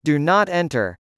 2. do not enter /duː nɑːt ˈentər/ : cấm vào